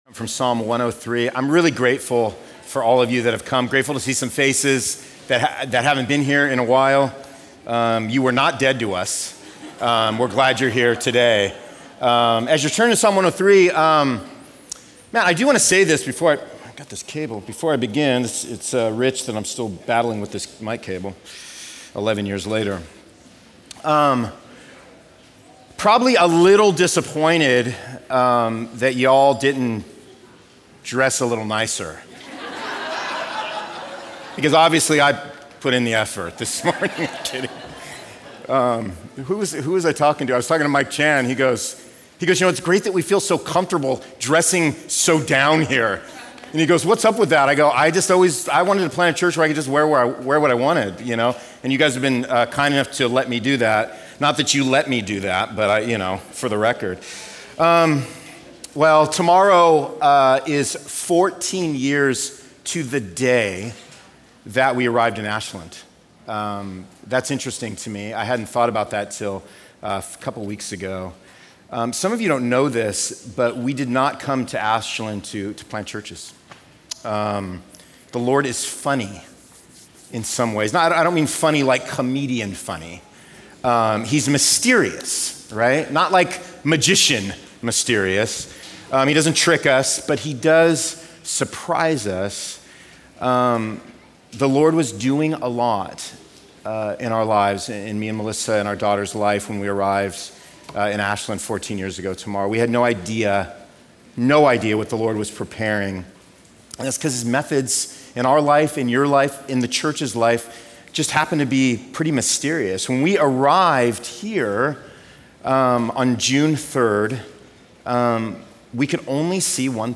Sunday Worship | Substance Church, Ashland, Ohio
Sermons